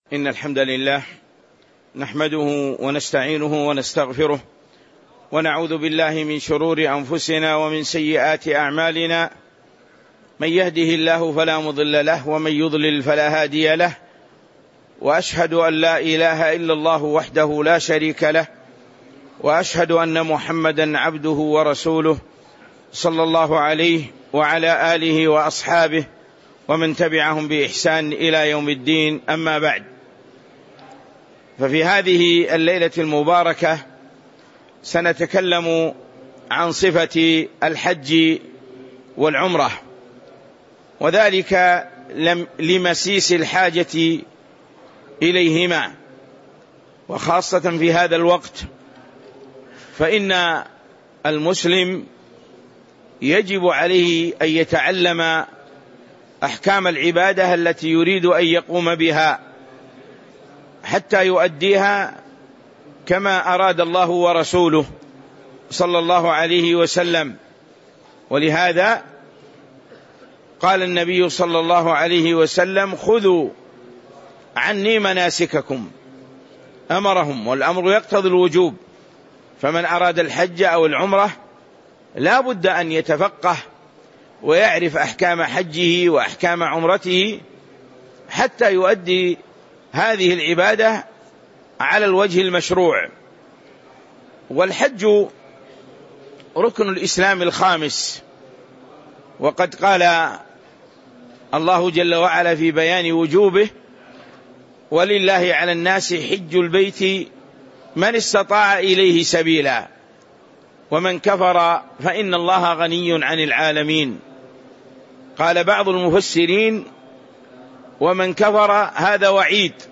تاريخ النشر ٢٠ ذو القعدة ١٤٤٦ هـ المكان: المسجد النبوي الشيخ